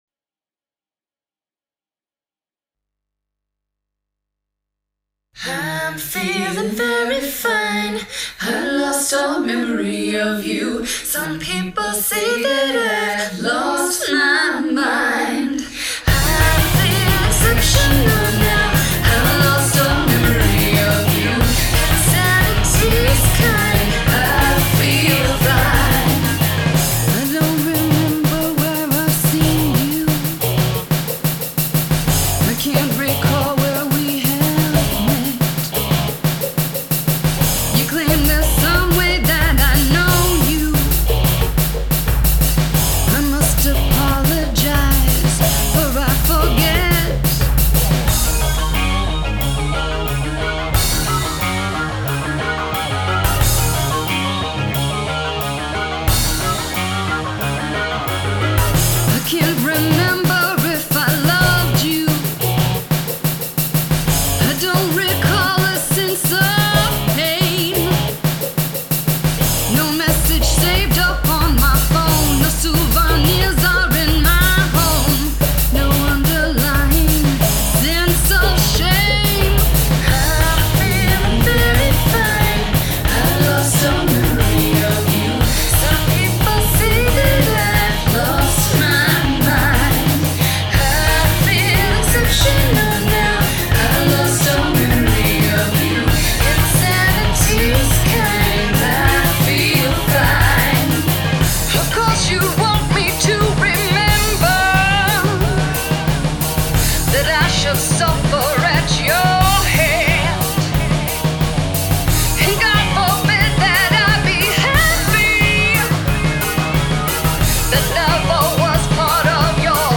RnB
Nu-metal